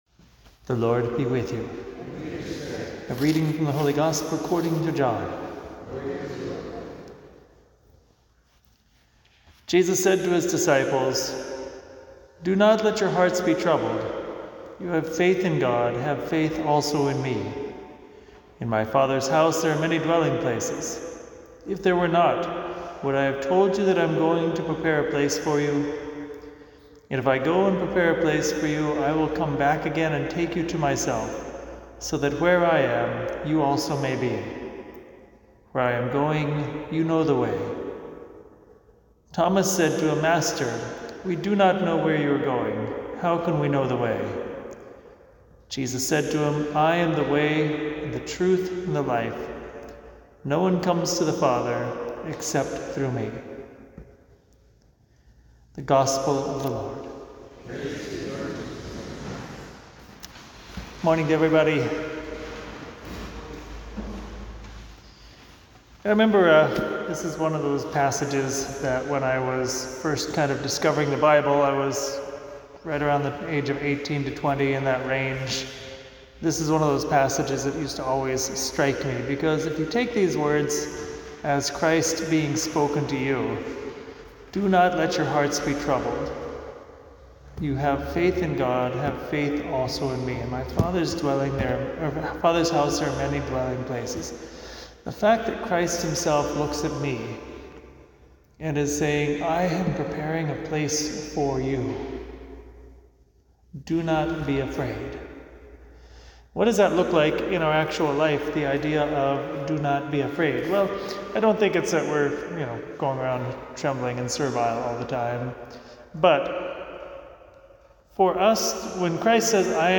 homily for September 8th, 2025.